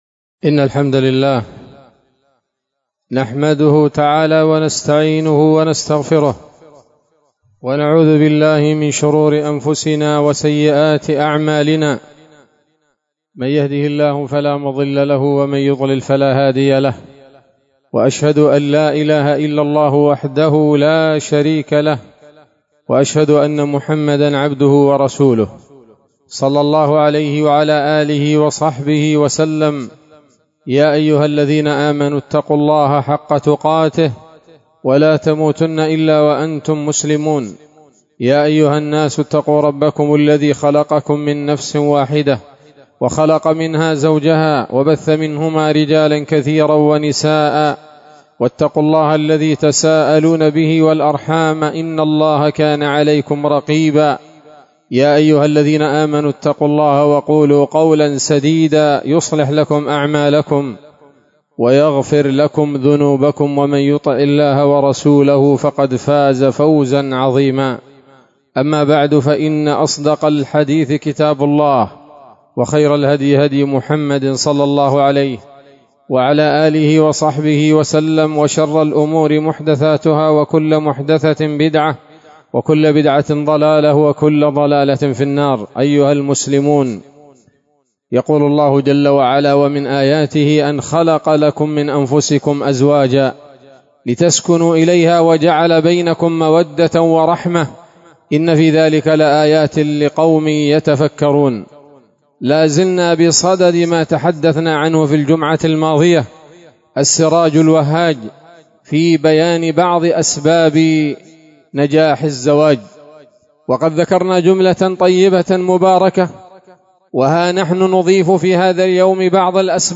خطبة جمعة بعنوان: (( السراج الوهاج في بيان أسباب نجاح الزواج [2] )) 28 صفر 1447 هـ، دار الحديث السلفية بصلاح الدين